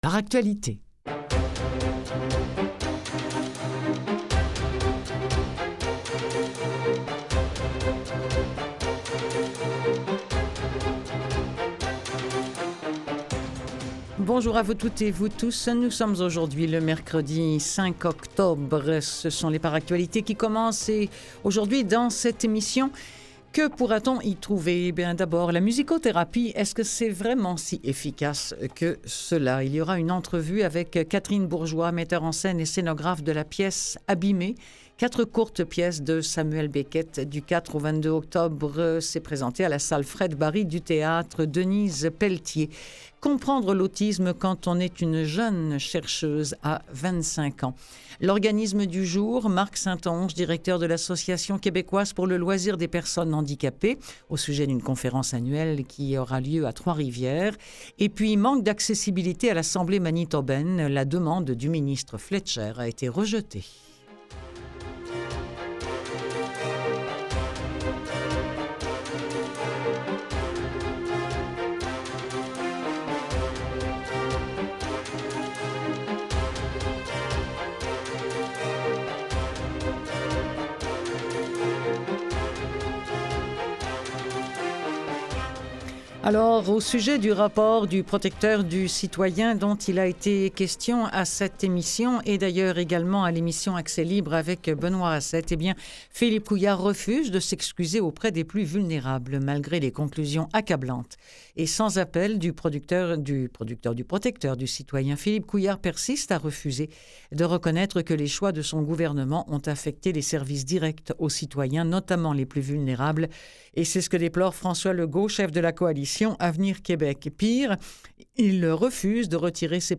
Les entrevues du jour